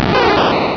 Cri de Bulbizarre dans Pokémon Rubis et Saphir.